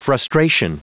Prononciation du mot frustration en anglais (fichier audio)
Prononciation du mot : frustration